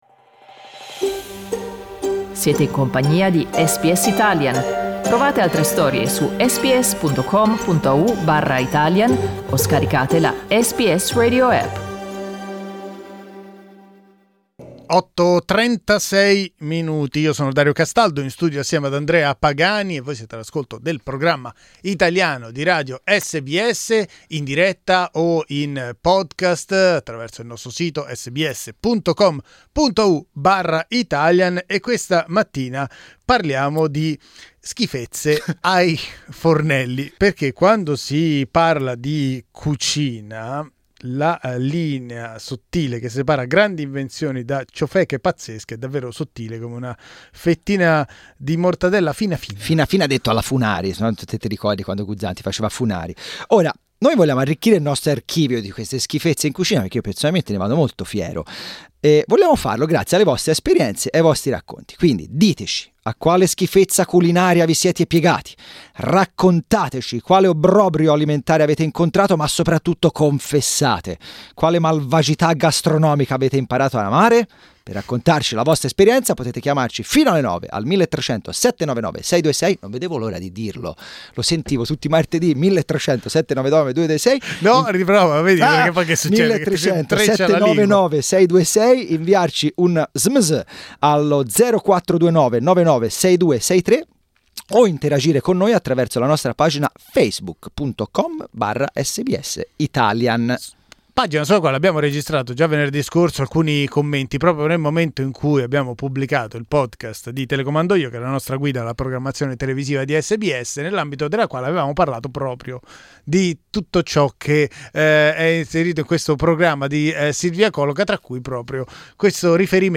Ascolta il nostro talkback sugli obbrobri culinari.